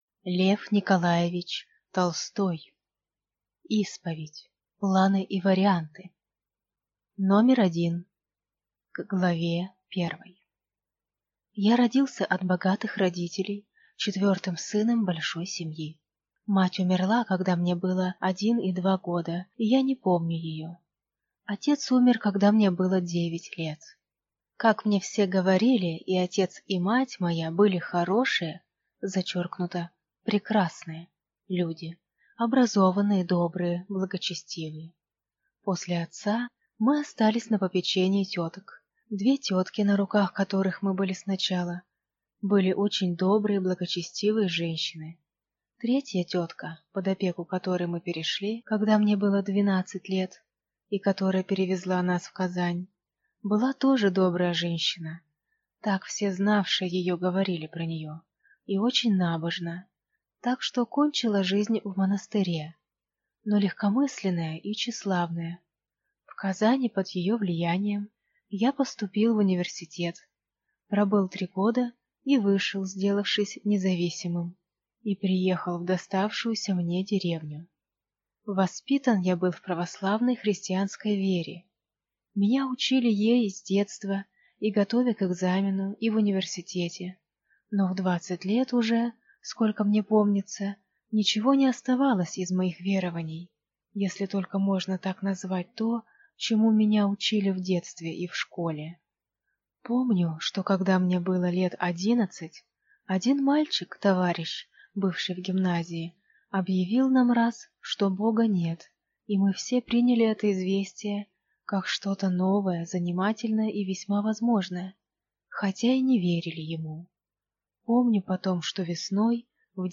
Аудиокнига Исповедь (Планы и варианты) | Библиотека аудиокниг